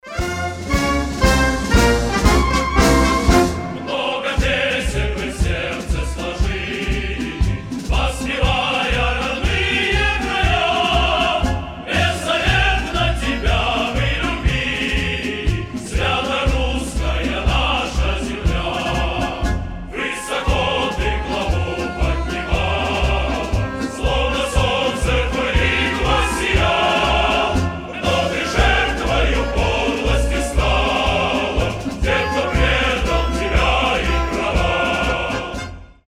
патриотические песни.
Хор
Оркестр
Марш